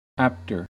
Ääntäminen
Ääntäminen US RP : IPA : /ˈæptə/ US : IPA : /ˈæptɚ/ Haettu sana löytyi näillä lähdekielillä: englanti Apter on sanan apt komparatiivi.